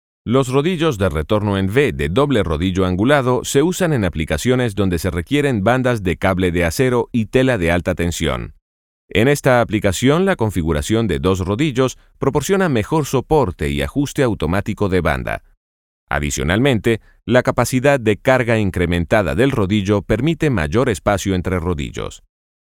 Male
Spanish (Latin American), Spanish (Venezuela)
Yng Adult (18-29), Adult (30-50)
Machinery Industrial Training
Male Voice Over Talent